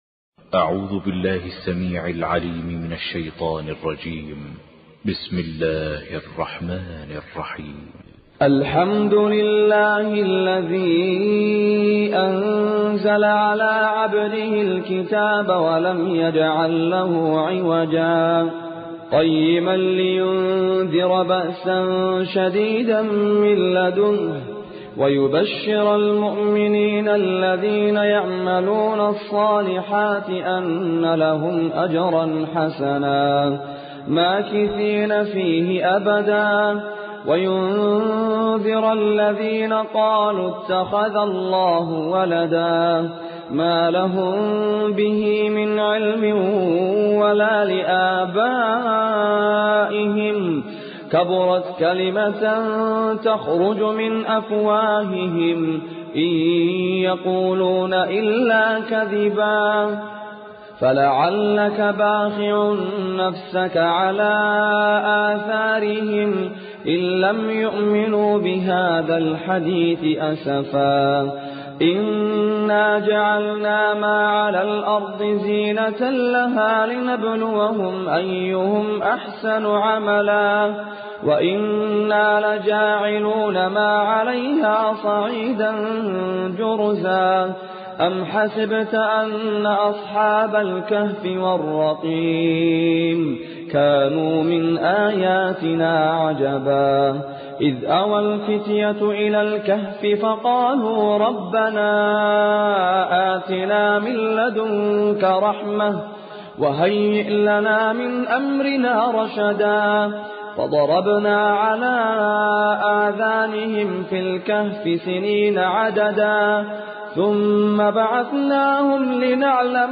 Surah Al Kahf, listen or play online mp3 tilawat / recitation in Arabic in the beautiful voice of Sheikh Abdul Aziz Az Zahrani.